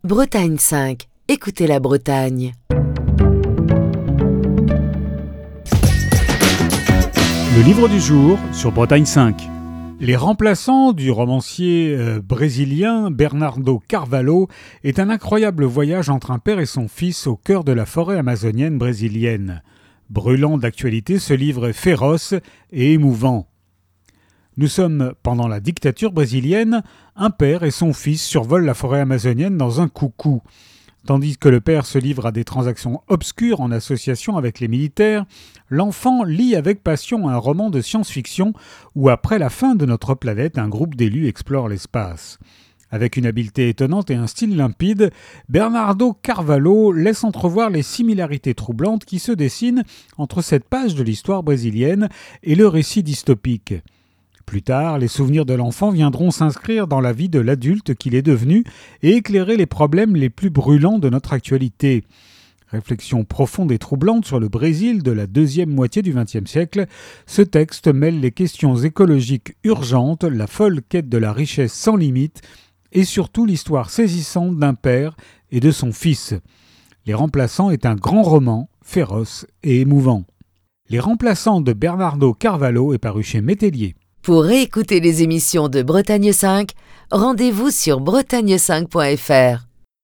Chronique du 18 décembre 2025.